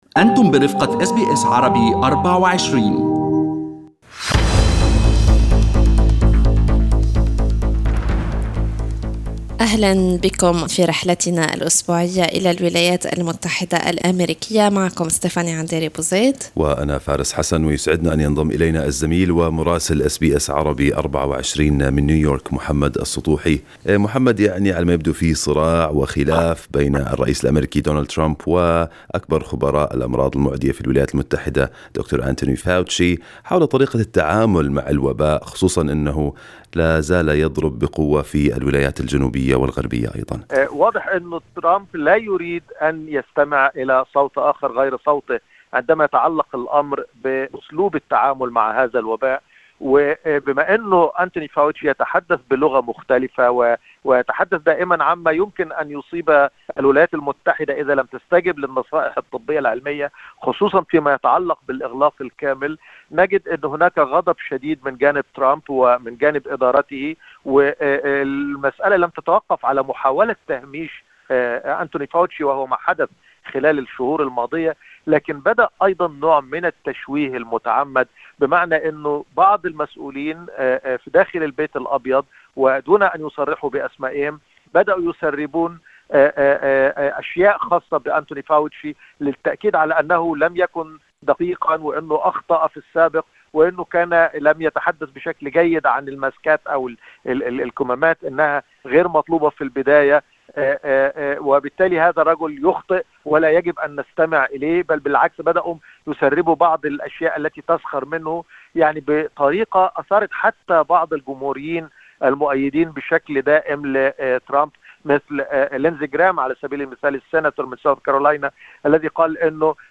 يمكنكم الاستماع إلى تقرير مراسلنا في الولايات المتحدة الأمريكية بالضغط على التسجيل الصوتي أعلاه.